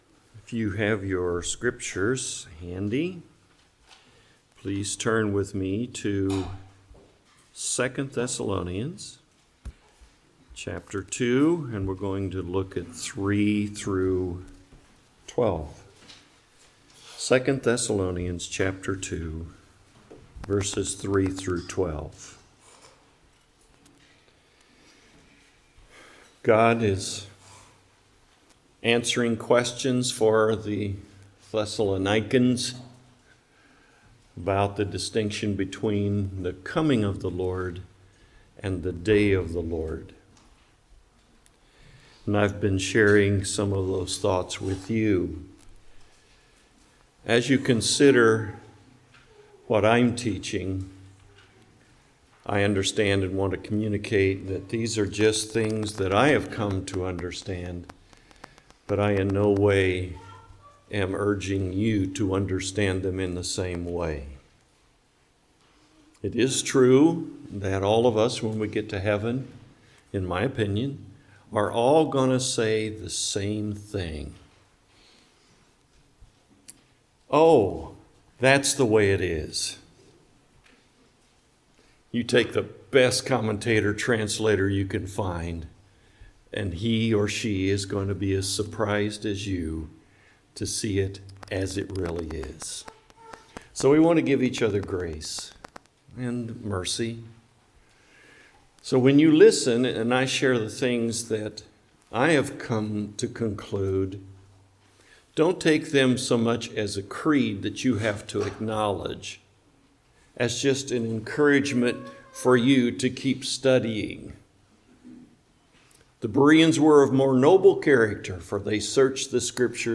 2 Thessalonians Passage: 2 Thessalonians 2:3-12 Service Type: Morning Worship